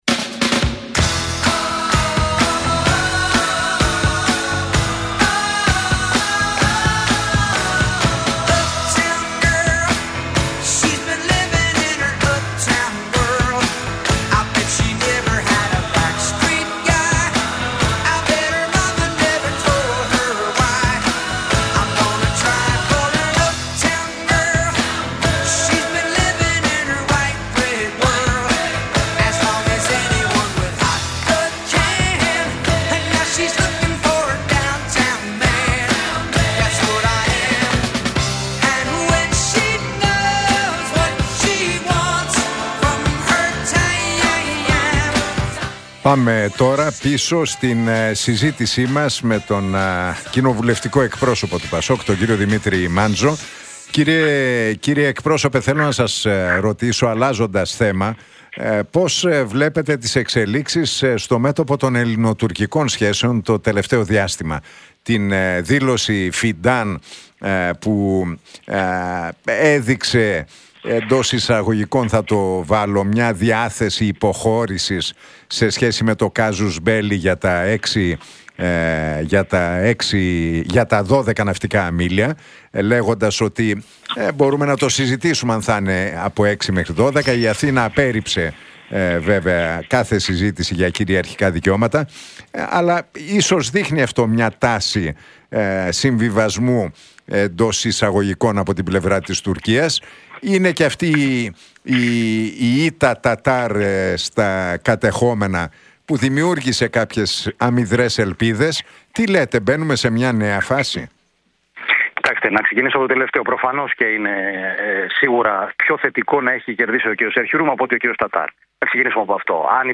Ακούστε την εκπομπή του Νίκου Χατζηνικολάου στον ραδιοφωνικό σταθμό RealFm 97,8, την Τρίτη 21 Οκτώβρη 2025.